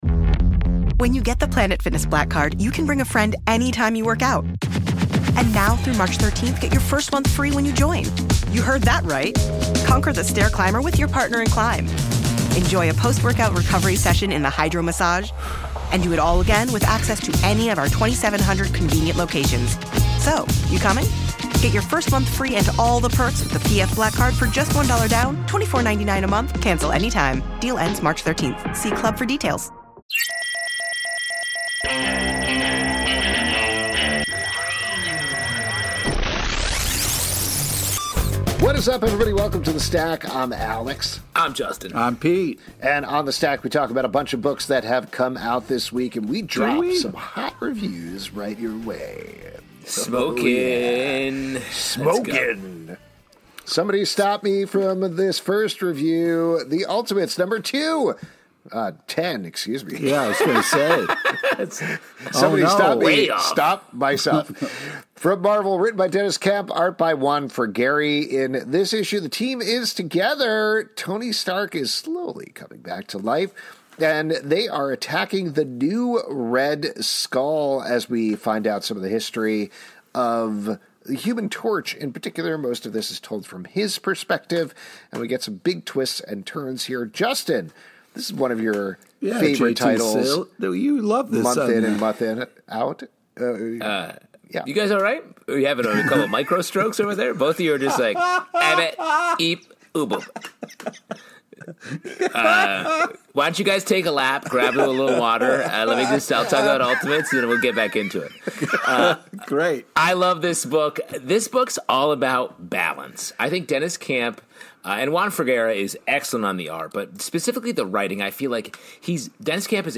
Comic Book Club is a LIVE weekly talk show about comic books, every Tuesday night at 7pm ET!